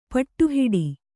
♪ paṭṭu hiḍi